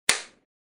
Звуки кинохлопушки
Звук хлопушки режиссера